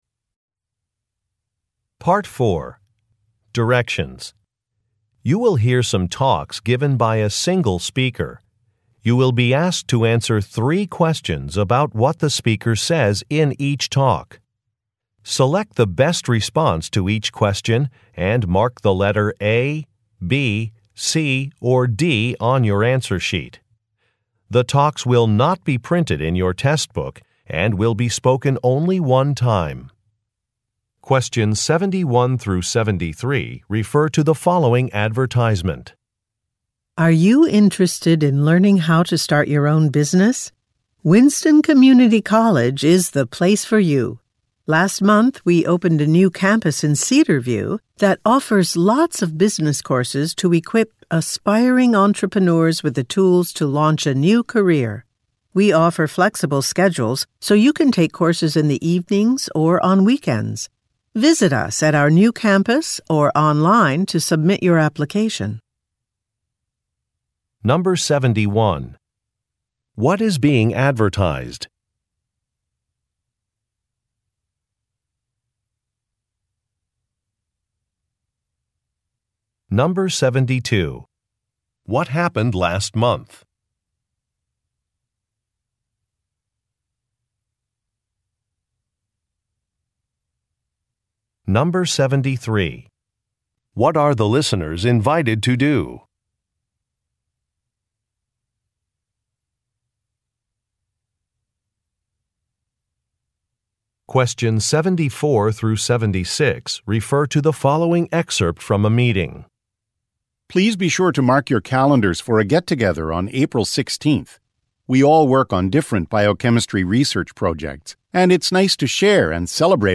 Directions: You will hear some talks given by a single speaker.
The talks will not be printed in your test book and will be spoken only one time.